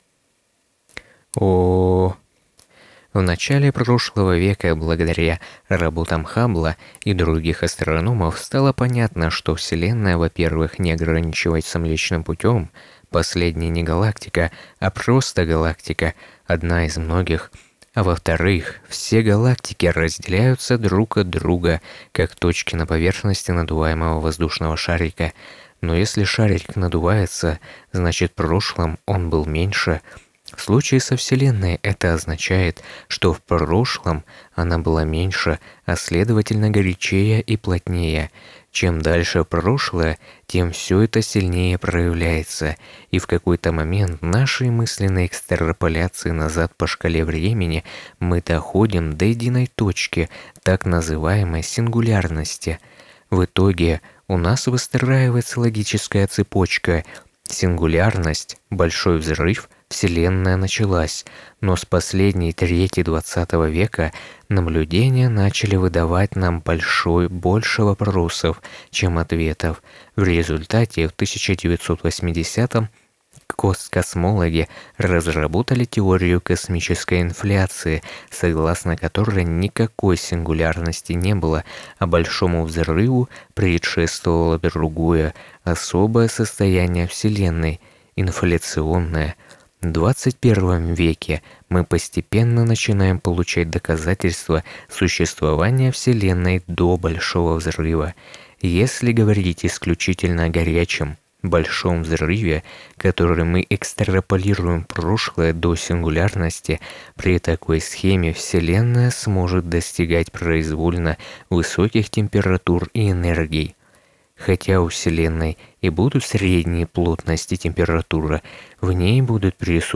Запись звука в октаву 105 и behringer umc204hd?
Но звук будто перегружен на низах, так понял нужен обрез низких частот или...